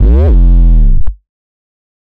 slidey808.wav